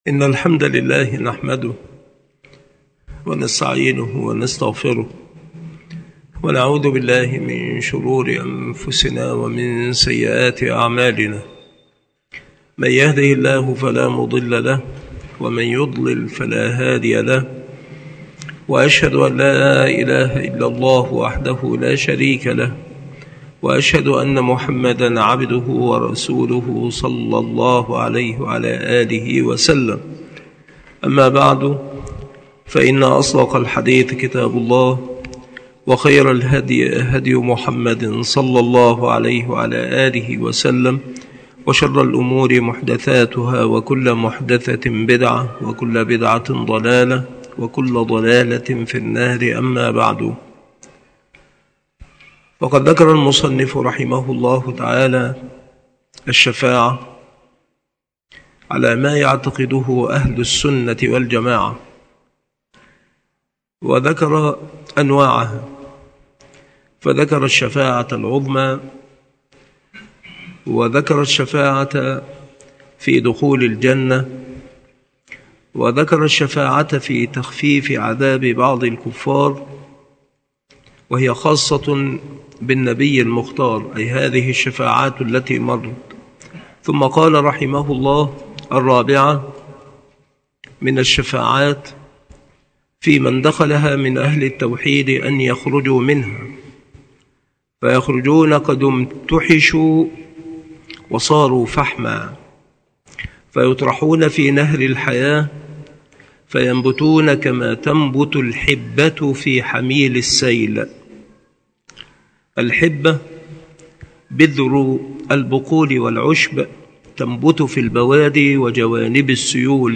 مكان إلقاء هذه المحاضرة بالمسجد الشرقي بسبك الأحد - أشمون - محافظة المنوفية - مصر عناصر المحاضرة : أنواع الشفاعة وأعظمها.